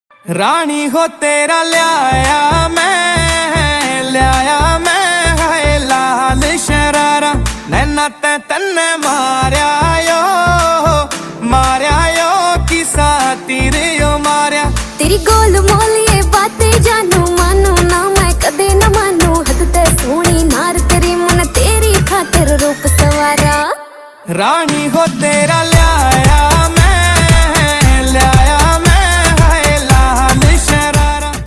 punjabi ringtone download mp3